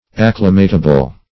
Acclimatable \Ac*cli"ma*ta*ble\, a. Capable of being acclimated.